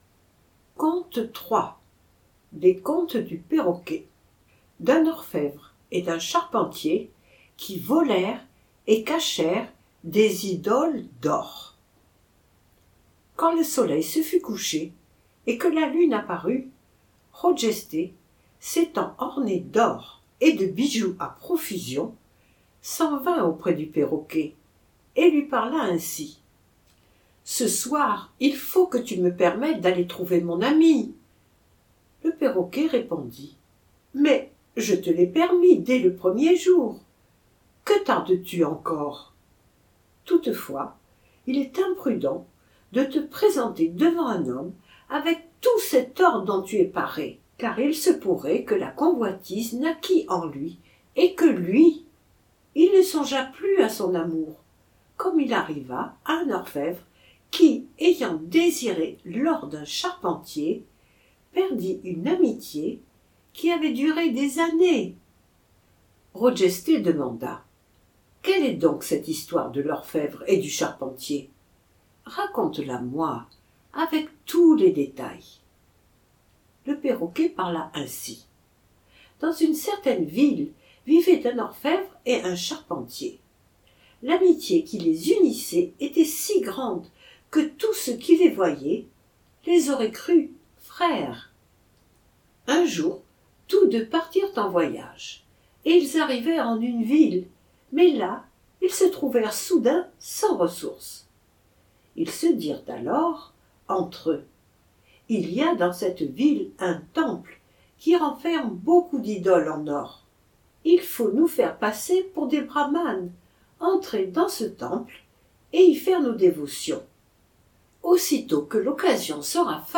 Livre audio gratuit : Conte du perroquet-3